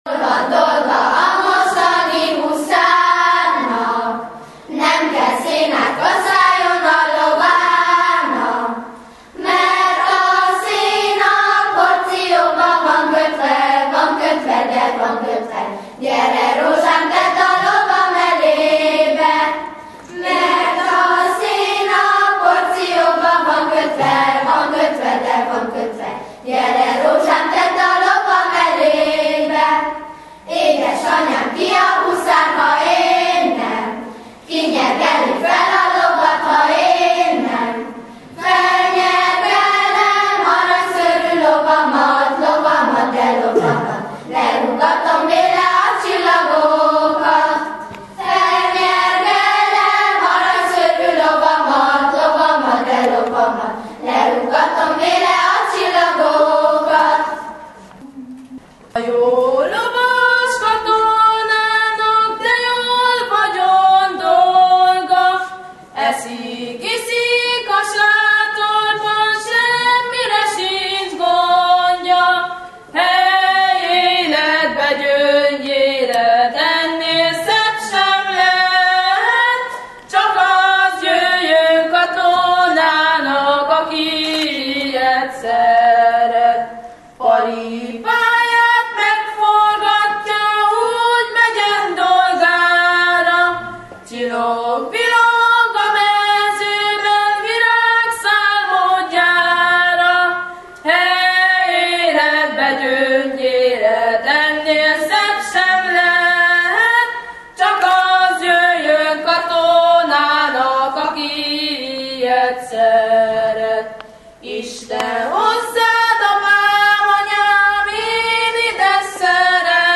A történelmi események felidézése után a Csiky gyermekkórusa énekelt 48-as dalokat, majd az V–VIII. osztályos diákok verses összeállítása következett.
Az iskolánk gyermekkórusa által március 15-én előadott dalokból itt hallgatható meg egy kis csokor:
marcius_15_korus.mp3